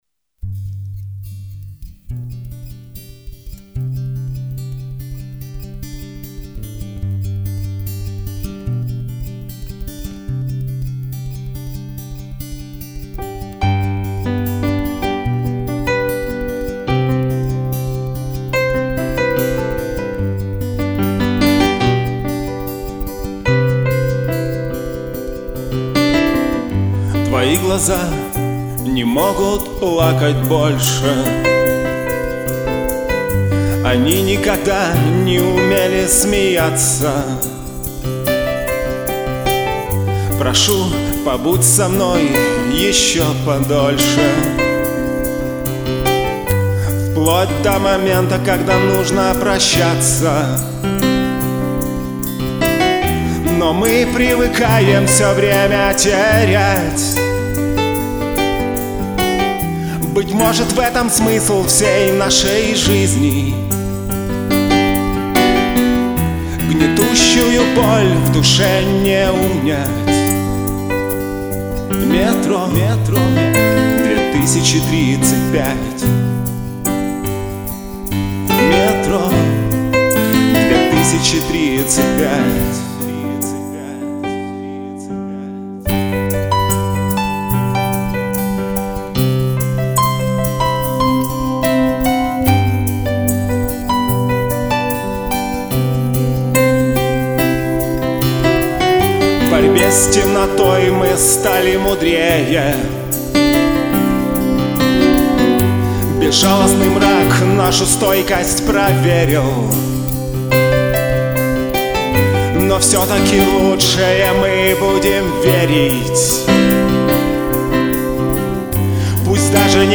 piano vers. 2017